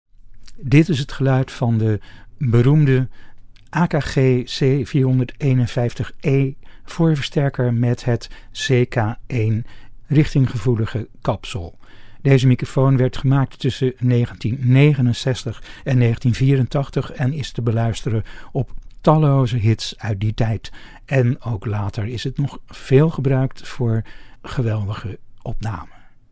Onder: klank, C451E met VR1 extensie en CK1, onderdelen en de fragiele schroefdraad van de C451
AKG C451E-CK1 sound NL.mp3